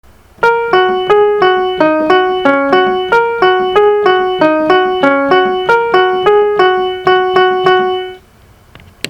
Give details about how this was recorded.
And here's what it sounds like when actually played.